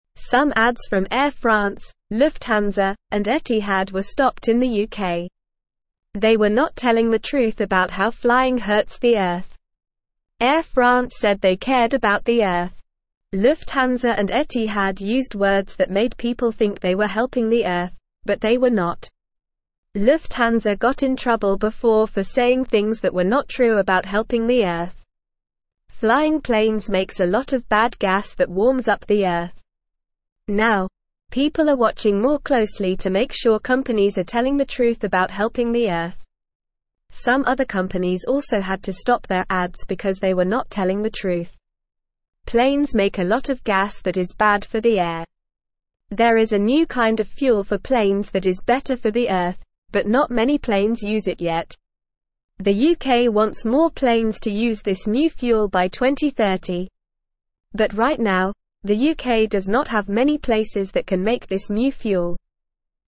Normal
English-Newsroom-Lower-Intermediate-NORMAL-Reading-Airplane-Companies-Ads-About-Being-Good-to-Earth-Were-Not-True.mp3